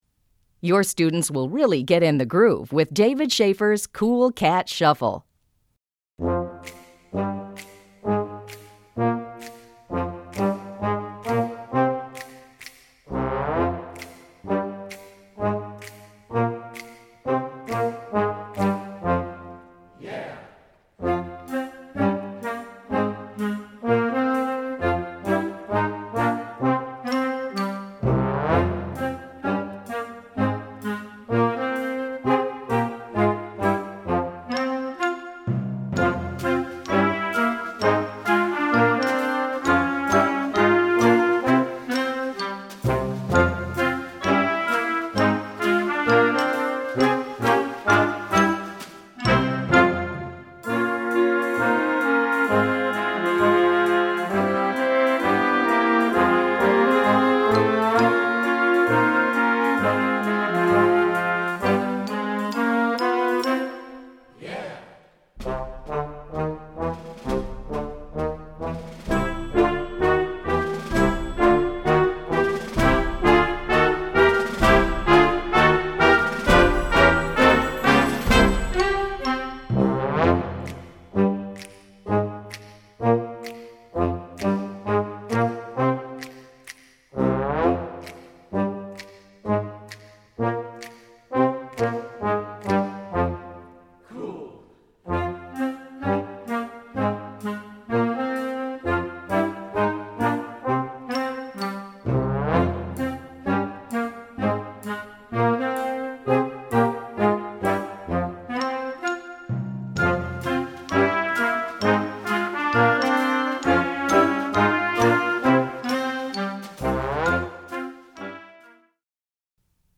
Categoría Banda sinfónica/brass band
Subcategoría Música de concierto / Música sinfónica